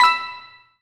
collect_coin_03.wav